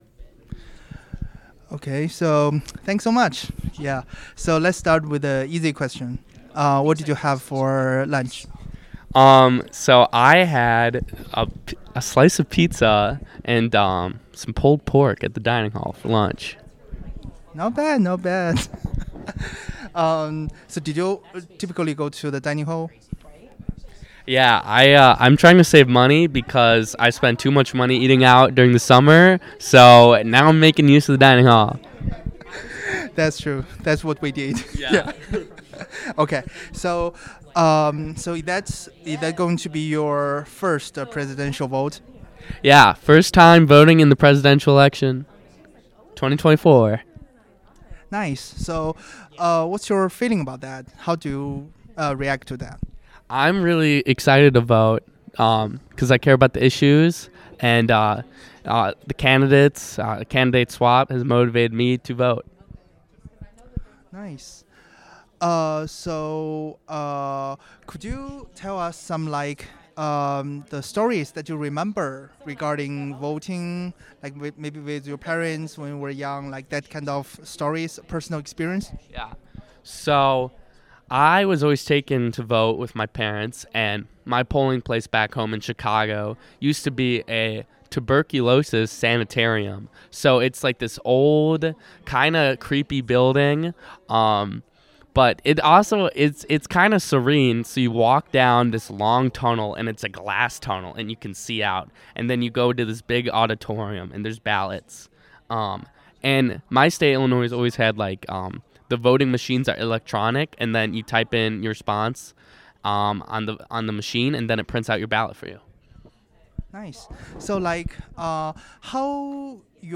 Location Milwaukee Central Library